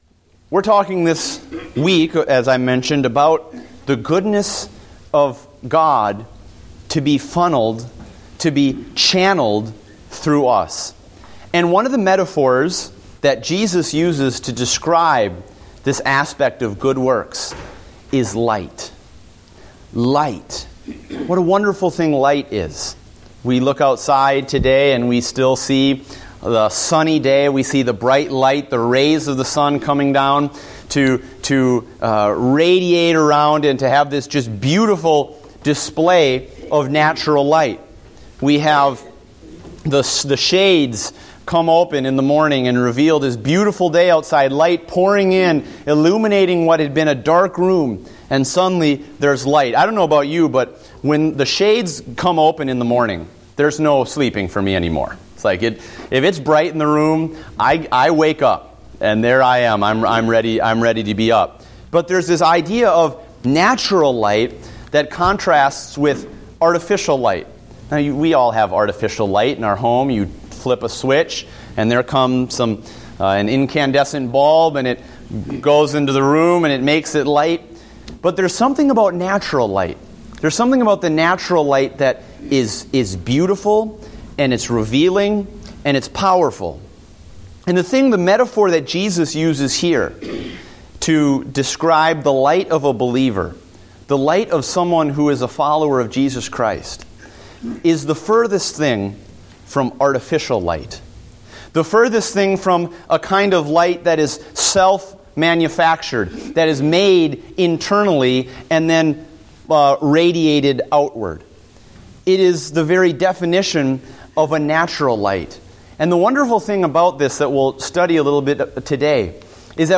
Date: September 28, 2014 (Adult Sunday School)